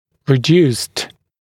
[rɪ’djuːst][ри’дйу:ст]уменьшенный, сниженный, пониженный